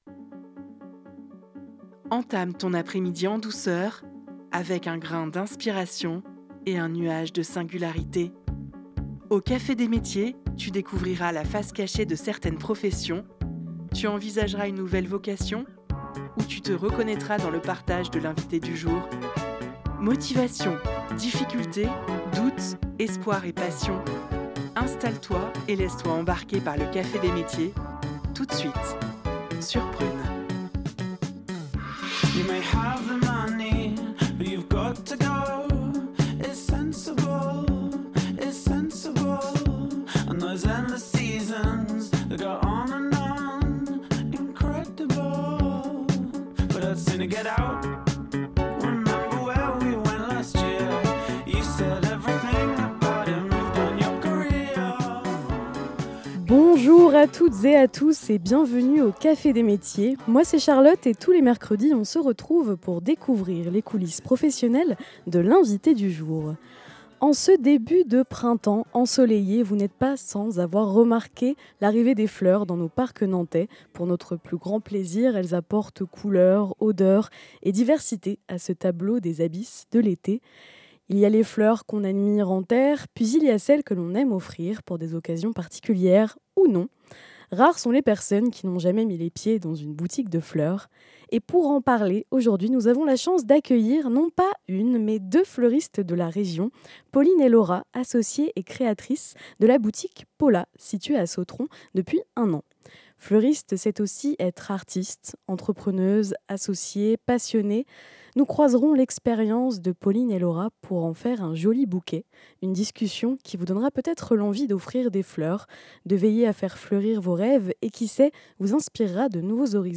:) Une discussion qui vous donnera peut-être envie d'offrir des fleurs, de veiller à faire fleurir vos rêves et qui sait, vous inspirera de nouveaux horizons professionnels.